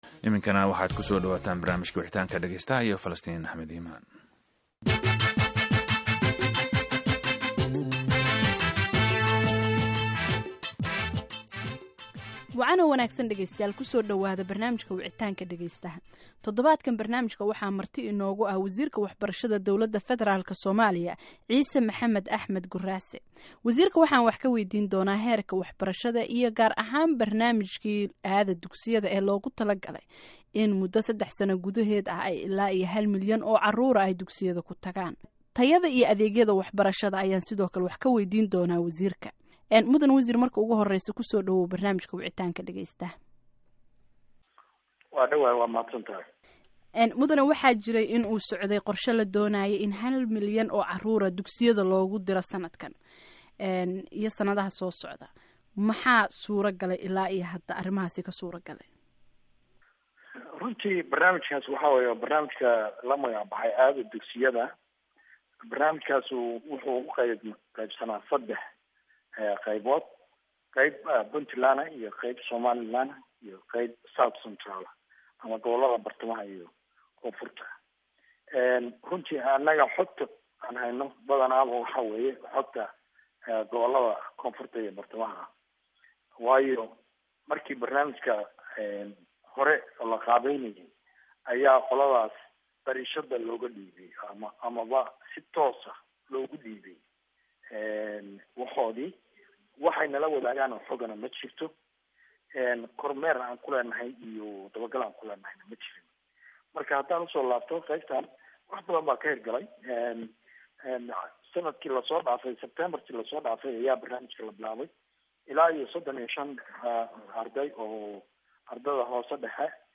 Barnaamijka Wicitaanka Dhageystaha ee toddobaadkan waxaa marti ku ah wasiirka waxbarashada ee dowladda federaalka Soomaaliya Ciise Maxamed Axmed "Gurraase" oo ay dhageystayaasha su'aalo waydiinayaa.